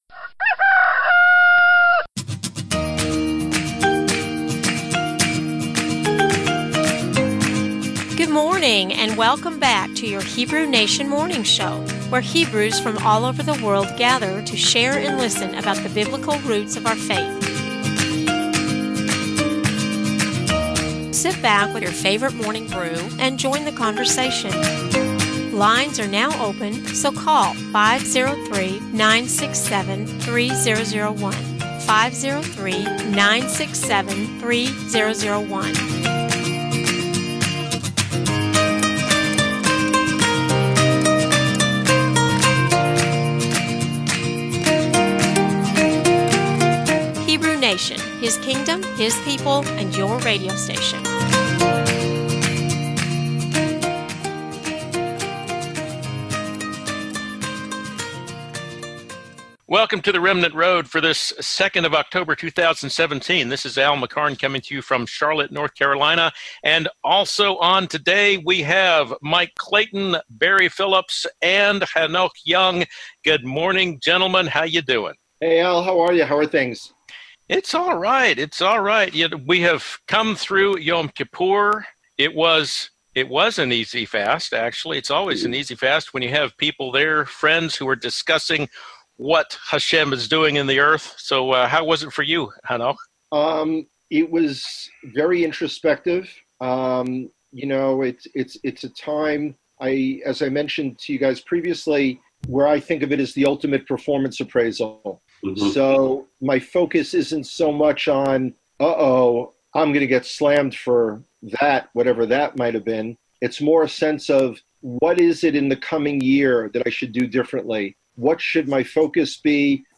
Another dynamite interview